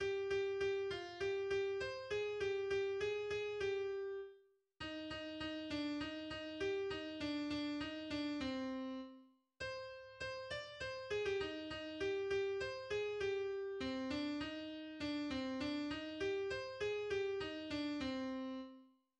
日本の童謡
ジャンル 童謡
楽しい行事を歌った曲で、題名にも「うれしい」とあるにもかかわらず、西洋音楽的に分類すれば短調である。